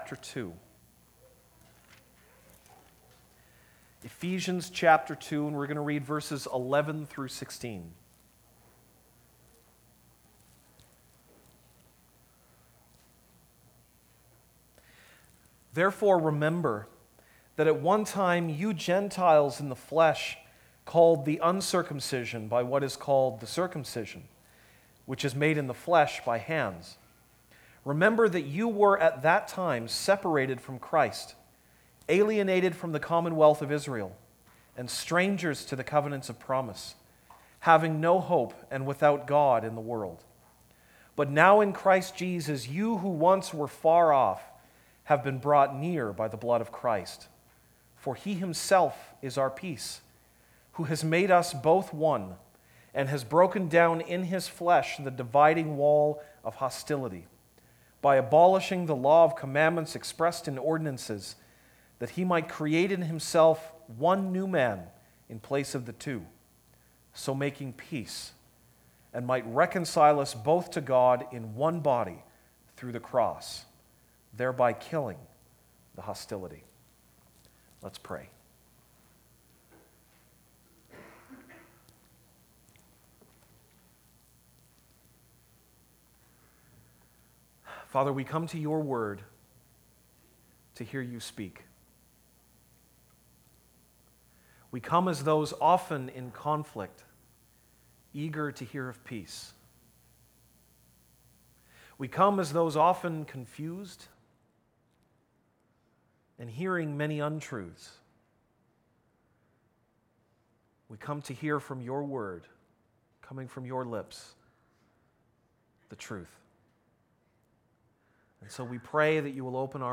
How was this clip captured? December 17, 2017 (Sunday Morning)